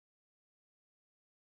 vocodedsound.wav